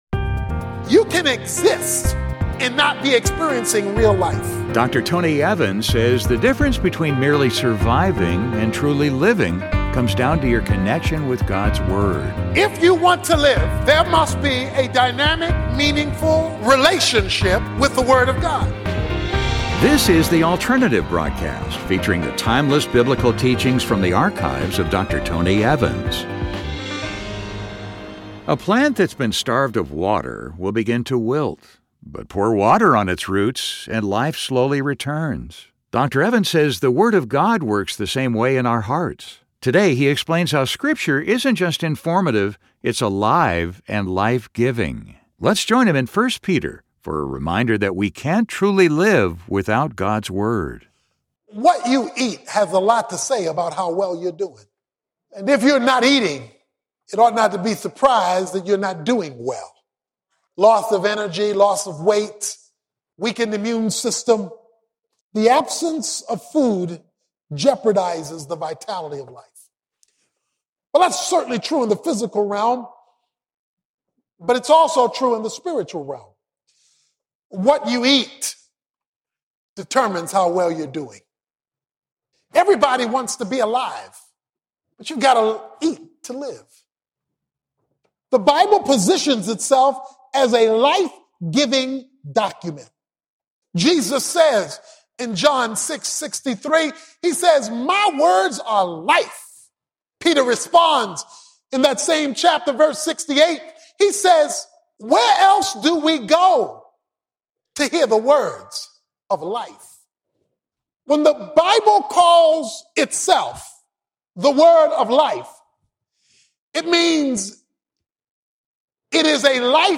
In this message, Dr. Tony Evans explores how the Word of God works the same way in our hearts.